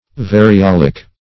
Search Result for " variolic" : Wordnet 3.0 ADJECTIVE (1) 1. relating to small pox ; [syn: variolar , variolic , variolous ] The Collaborative International Dictionary of English v.0.48: Variolic \Va`ri*ol"ic\, a. (Med.)